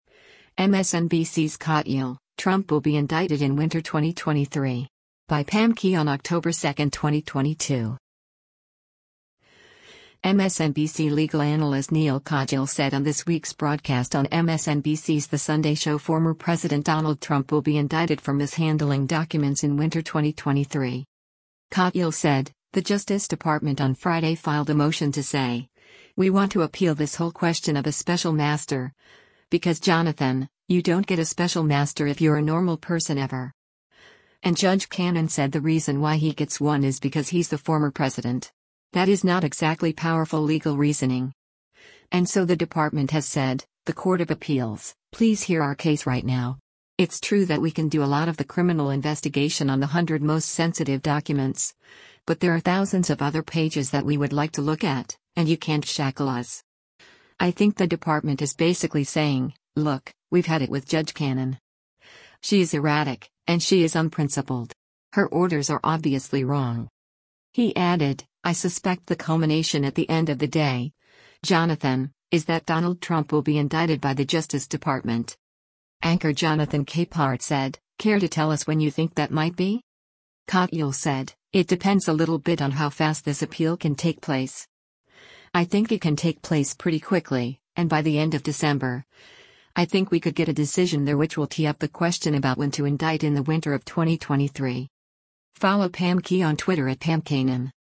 MSNBC legal analyst Neal Katyal said on this week’s broadcast on MSNBC’s “The Sunday Show” former President Donald Trump will be indicted for mishandling documents in Winter 2023.